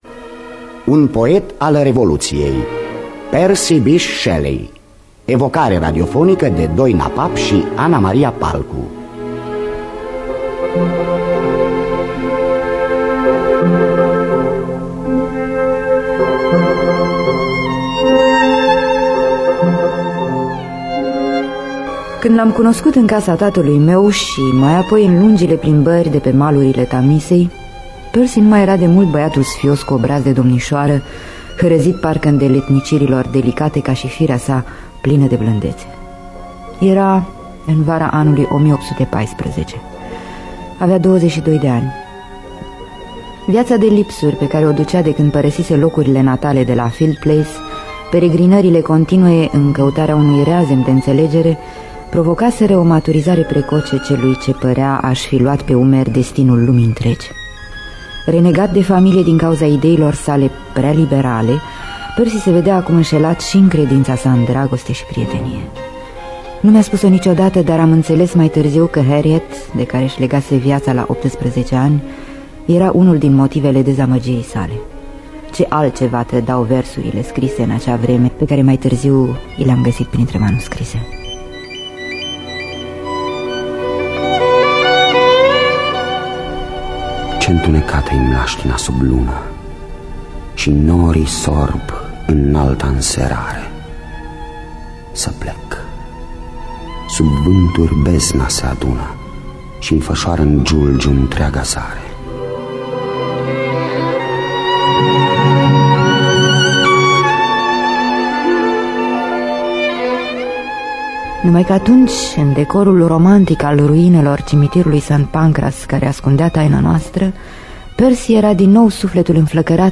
Biografii, memorii: Percy Bysshe Shelley – Un poet al revoluției. Scenariu radiofonic de Doina Papp și Ana Maria Palcu.